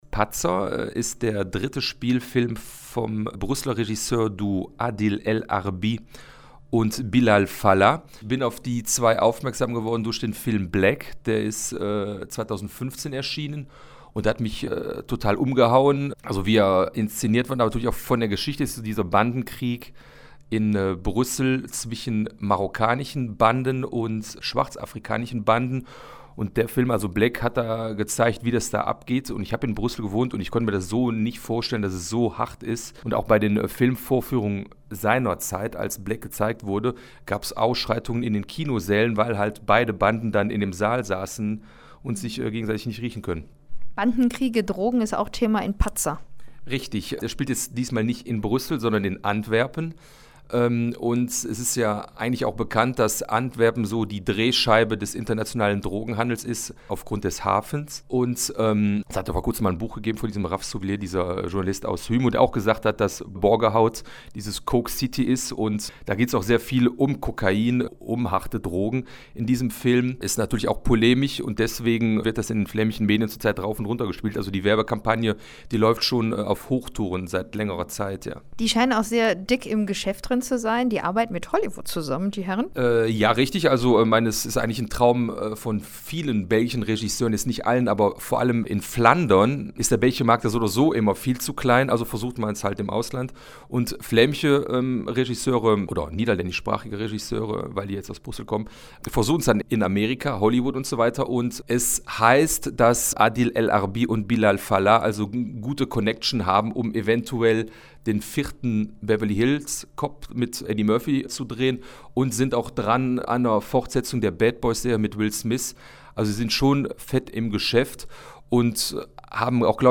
fragt